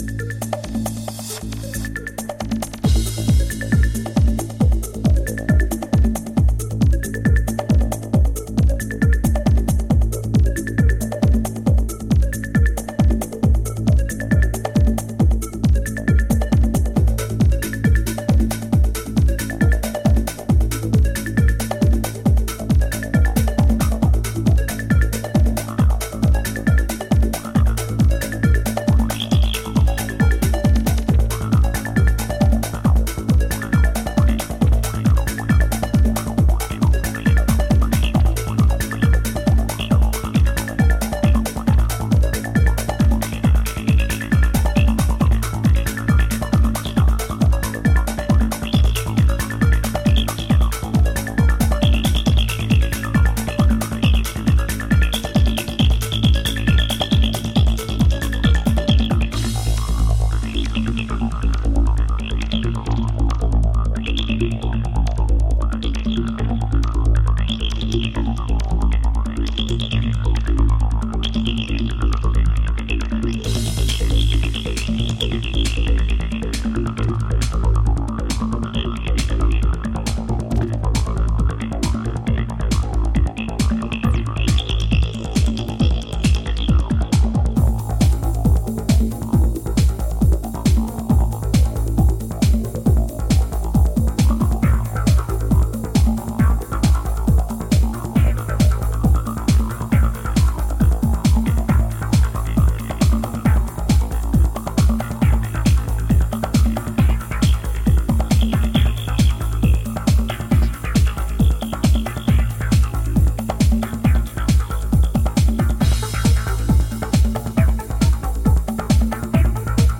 which rolls low and heavy like a good trance record should
psy-tinged